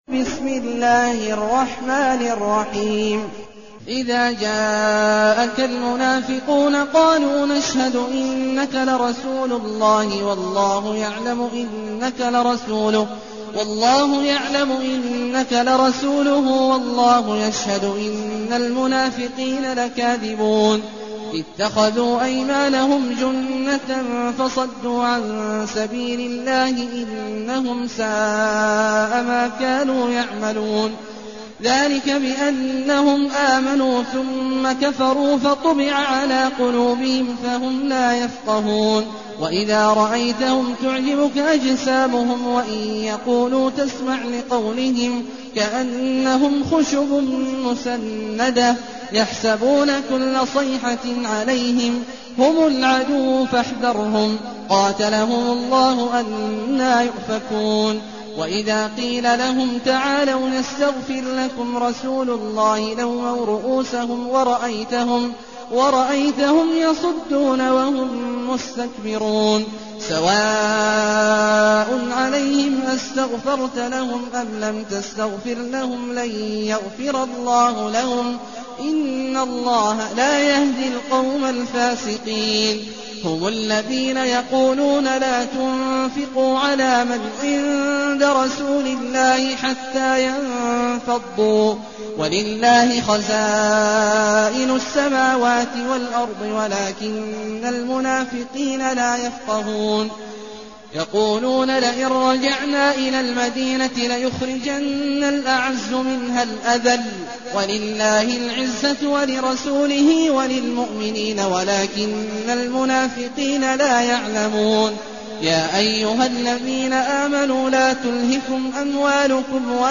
المكان: المسجد الحرام الشيخ: عبد الله عواد الجهني عبد الله عواد الجهني المنافقون